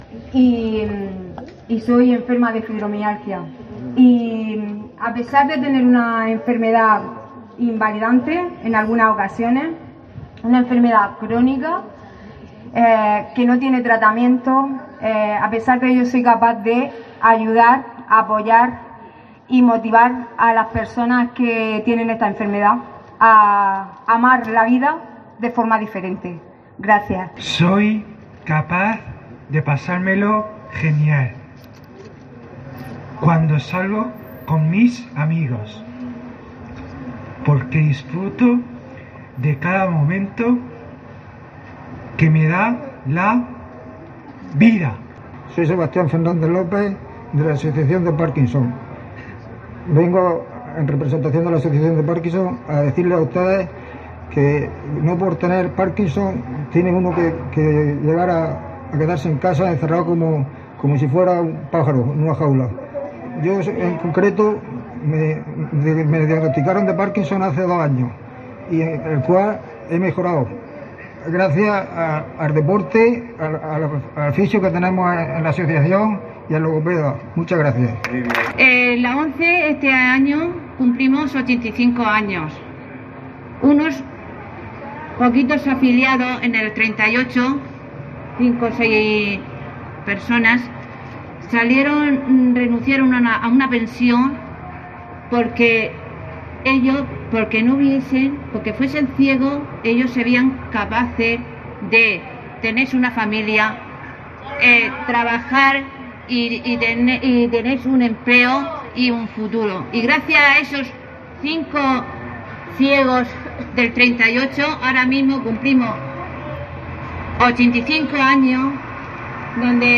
Belén Pérez, edil Oenegés " buscamos una jornada de concienciación, centrándonos en las capacidades y no en las discapacidades
Diferentes asociaciones lorquinas de discapacitados se reunieron en la Plaza Calderón de la Barca de Lorca para gritar todos juntos "Somos Capaces".
Aplausos, sonrisas y mucha emoción han proporcionado el contexto perfecto de la jornada participativa organizada por el consistorio lorquino y estas asociaciones, bajo el lema “Lorca Capaz”, con motivo de la conmemoración del Día Internacional de las Personas con Discapacidad, que llega cada año el 3 de diciembre.